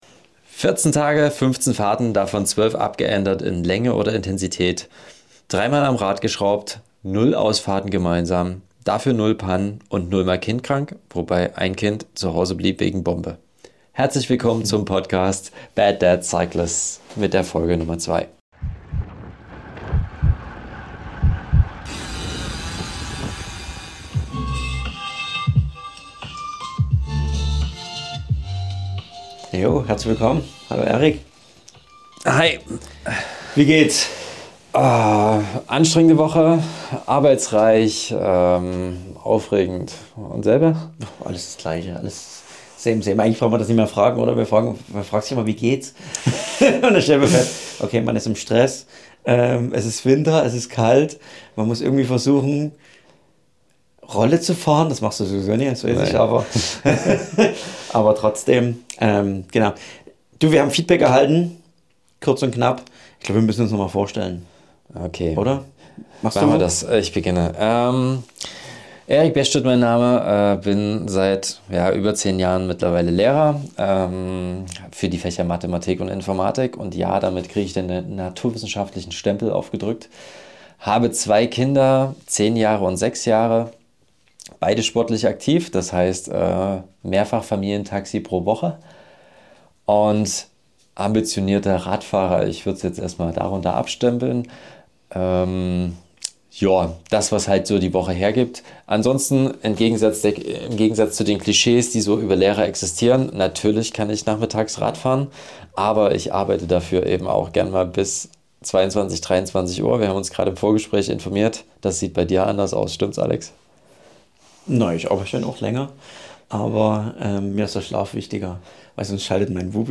In dieser Episode nehmen zwei Familienväter die Zuhörer mit auf eine spannende Reise der Familienurlaub-Planung. Doch dieses Mal geht es nicht nur um Erholung – die beiden wollen ihre Leidenschaft für den Radsport mit dem Familienurlaub verbinden.